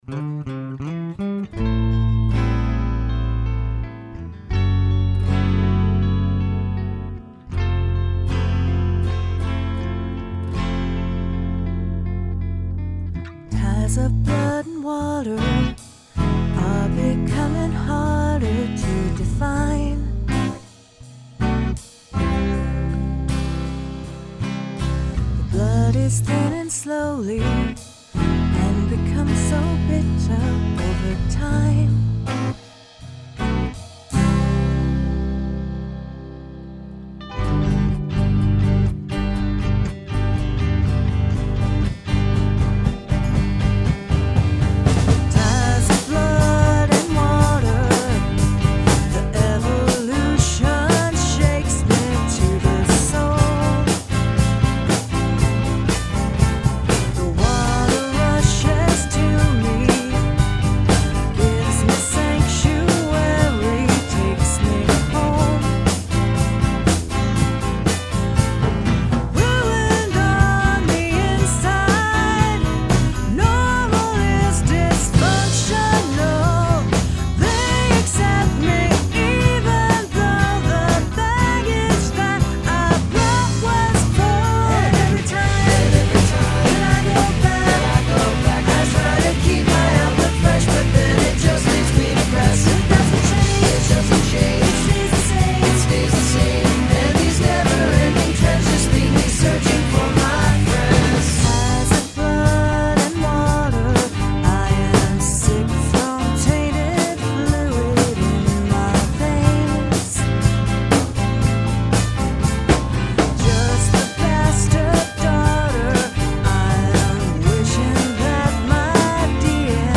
Must include three different tempos
(fast tempo)! Slow tempos